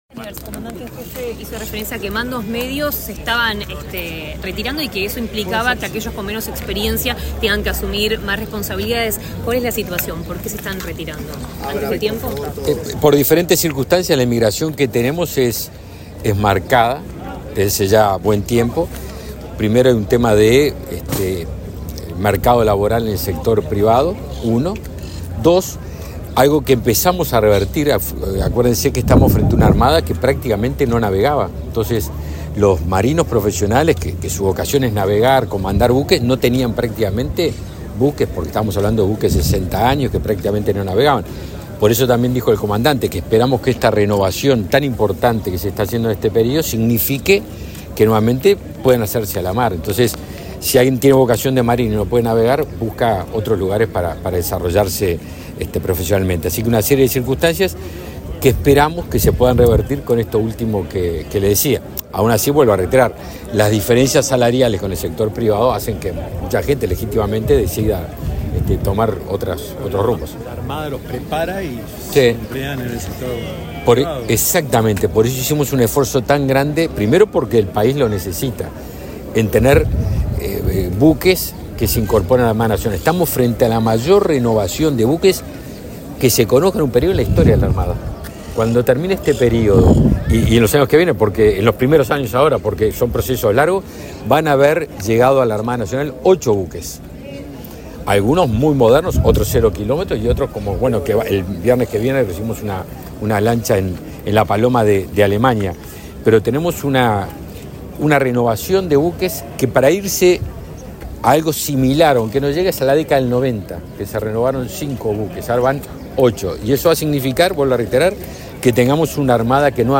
Declaraciones del ministro de Defensa Nacional, Javier García 15/11/2023 Compartir Facebook X Copiar enlace WhatsApp LinkedIn El ministro de Defensa Nacional, Javier García, dialogó con la prensa, luego de participar, este miércoles 15 en Montevideo, de la ceremonia del Día de la Armada Nacional.